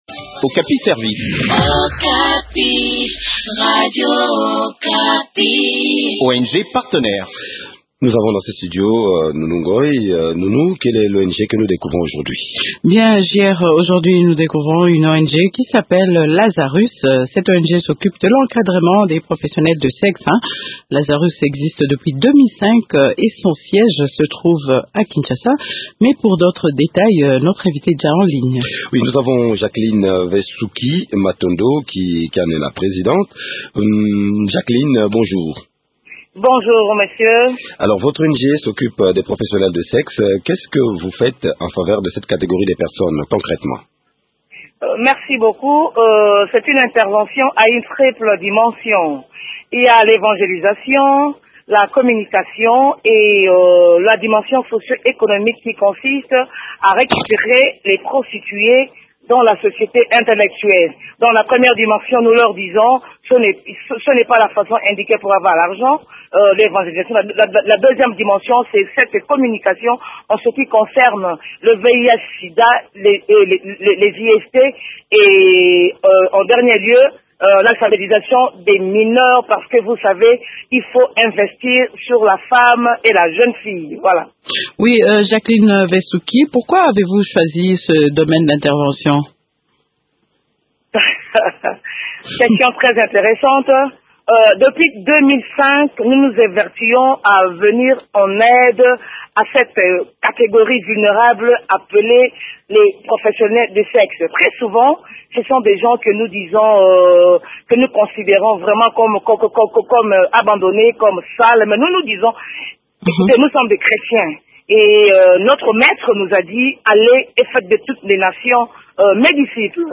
Découvrez les activités les activités de cette ONG dans cet entretien